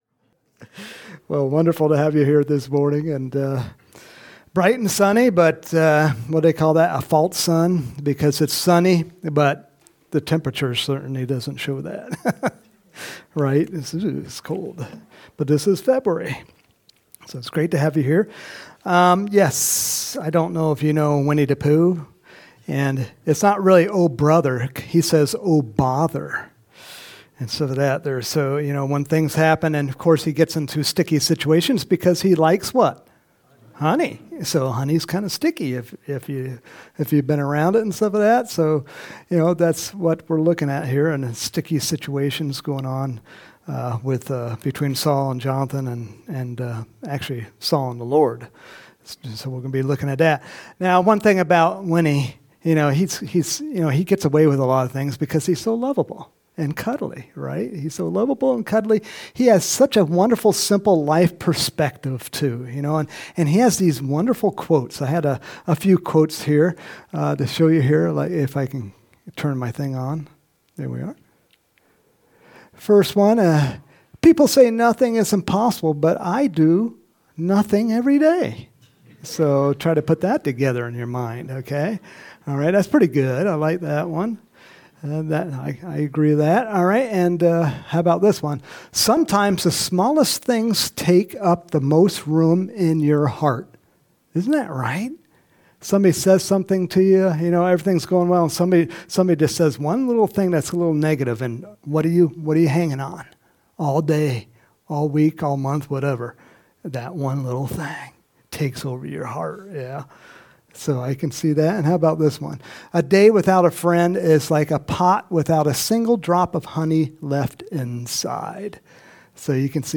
Home › Sermons › February 18, 2024